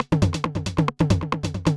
K-5 909 Loop.wav